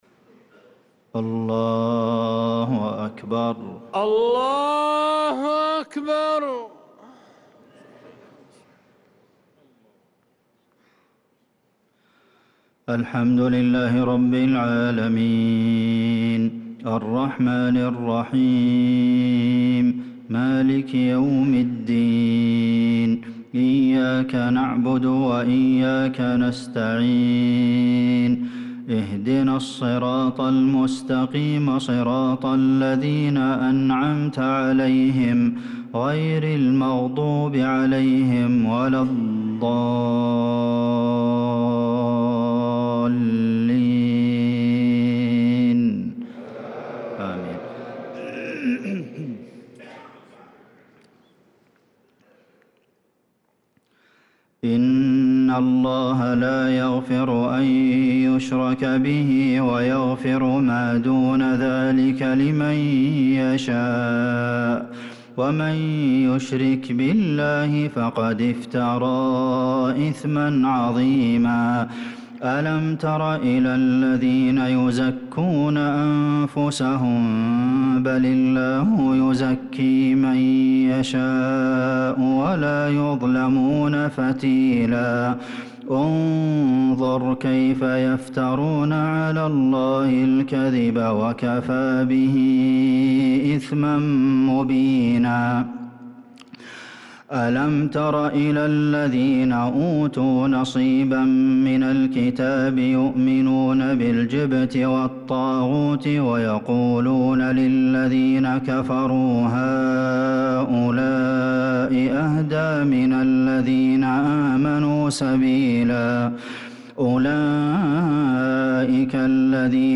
صلاة العشاء للقارئ عبدالمحسن القاسم 8 رجب 1445 هـ
تِلَاوَات الْحَرَمَيْن .